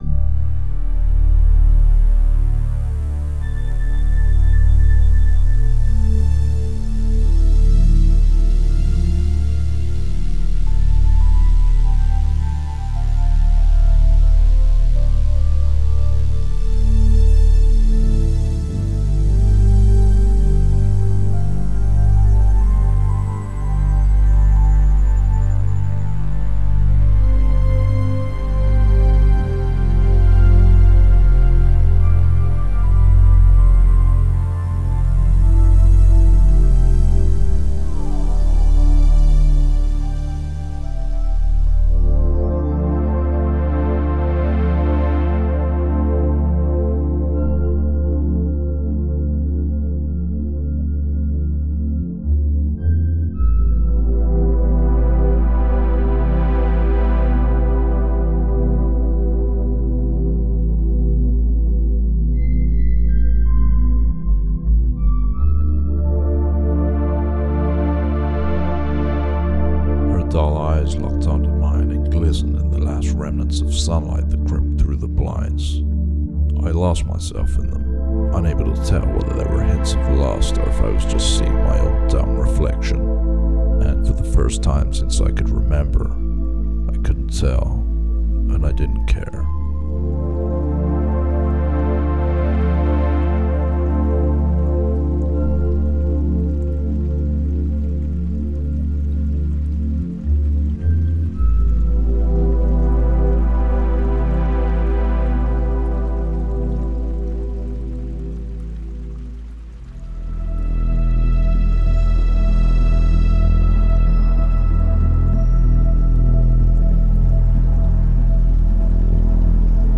FX / 电影
具有专业配音，六个迷你制作套件和精选的SFX，这是针对配乐作曲家和实验制作人的示例性听觉享受。
数月的计划和录音室时间专门用于重新想象，而不是模仿反乌托邦洛杉矶的合成器声景。
•专业配音